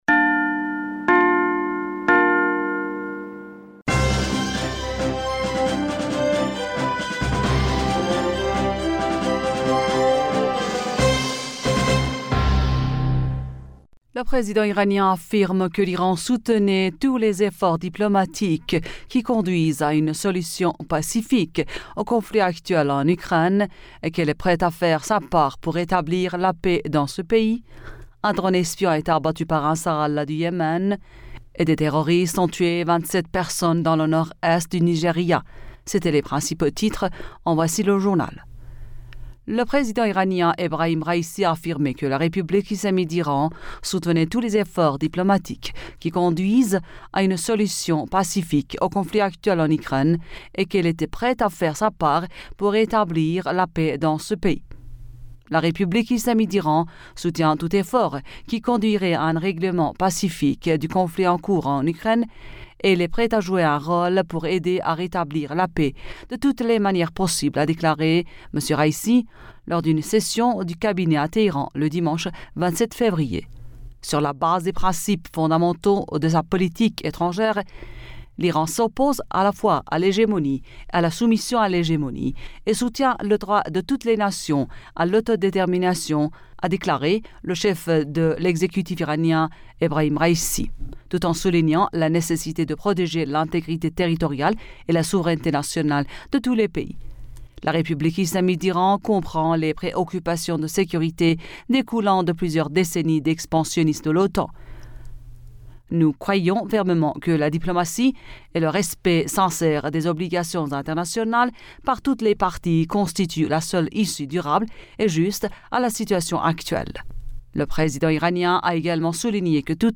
Bulletin d'information Du 28 Fevrier 2022